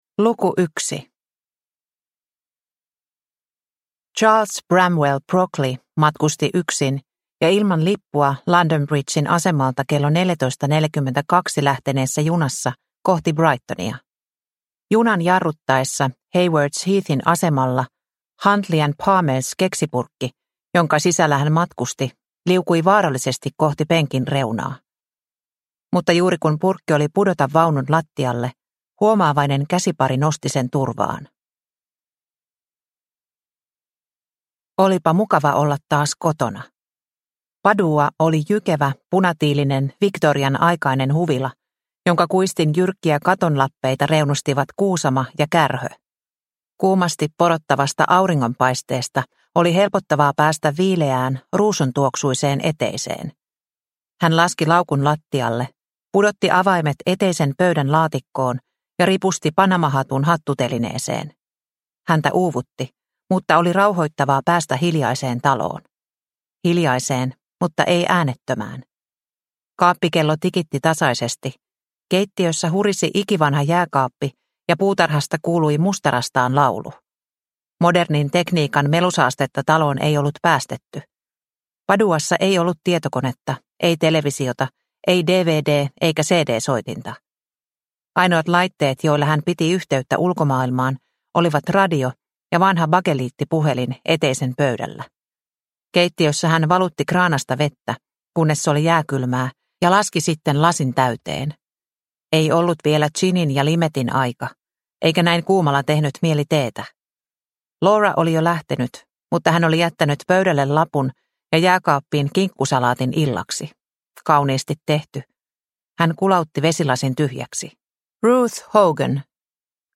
Kadonneiden tavaroiden vartija – Ljudbok – Laddas ner